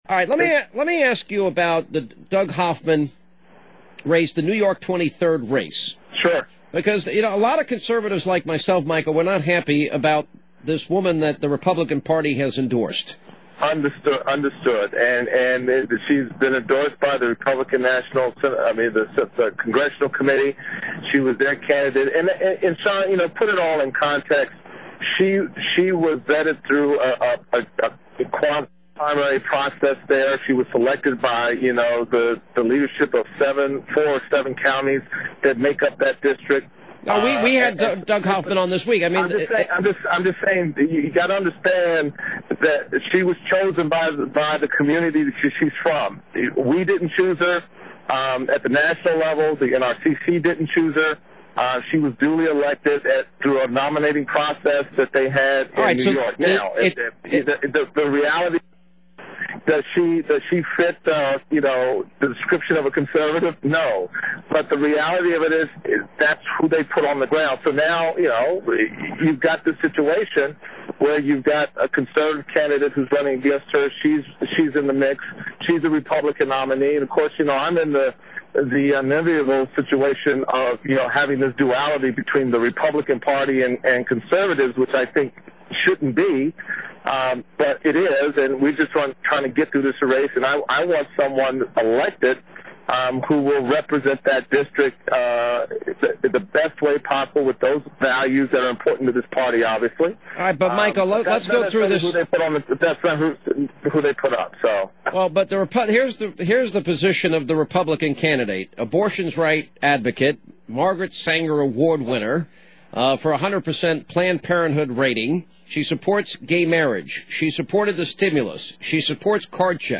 NY-23: Steele factually wrong while talking with Hannity; Scozzafava is for card check
Today, Hannity asked him about the Republican National Committee and the National Republican Congressional Committee's support of the liberal Republican in the race against Conservative Party and Republican Doug Hoffman for the House seat vacated by John McHugh. Here's a brief excerpt; listen closely to Steele disputing Hannity about Scozzafava's position on card check: